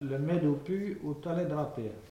Langue Maraîchin
Locution